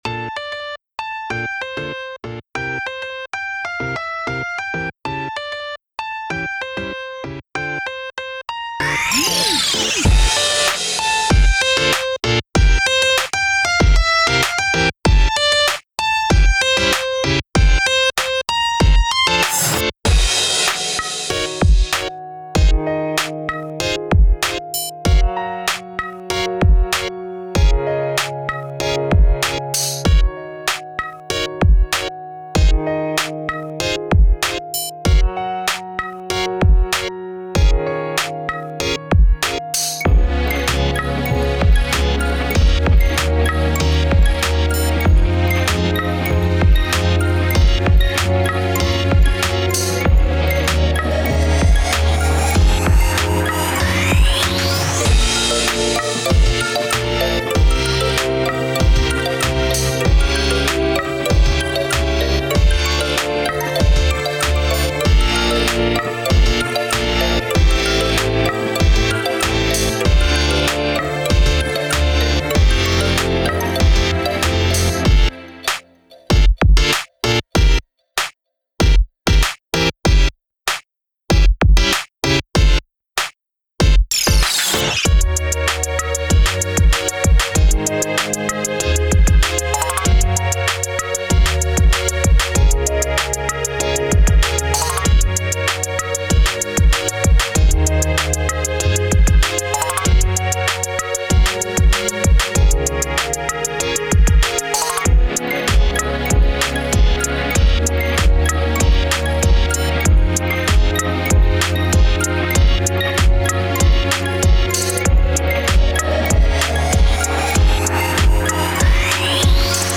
BPM：96